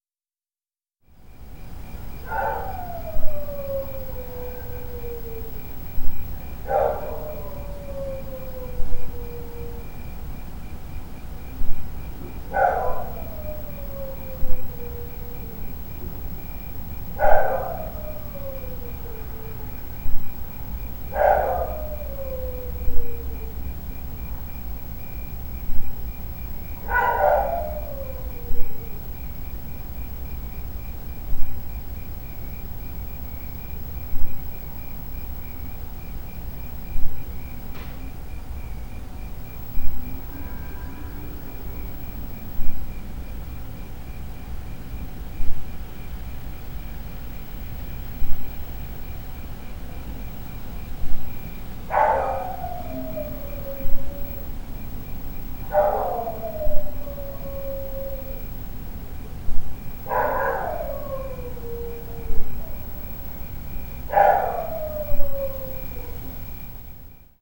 Proposição sonora: coleção (em processo) de uivos de cachorros (alternando-se 17 minutos de sons de uivos com 17 minutos de silêncio)
6. uivo solo com pausa
6_uivo_solo_com_pausa.wav